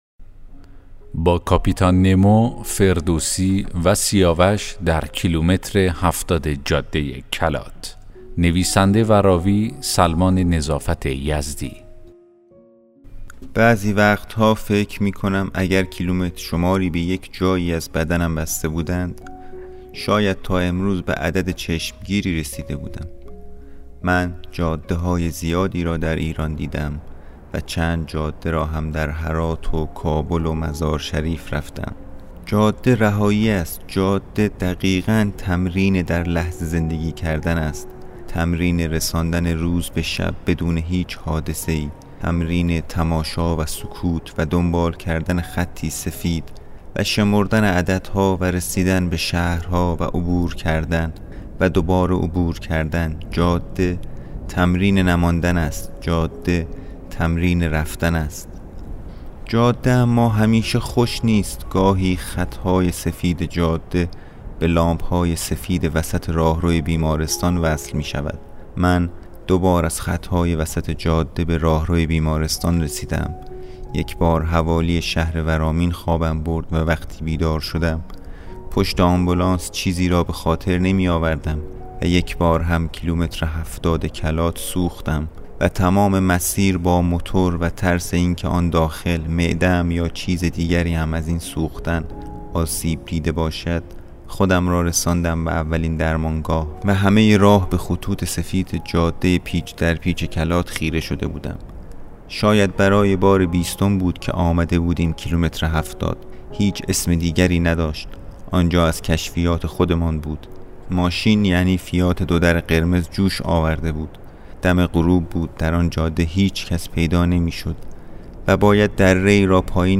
داستان صوتی: با کاپیتان نمو، فردوسی و سیاوش در کیلومتر ۷۰ جاده کلات